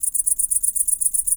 INSECT_Crickets_Segment_04_mono.wav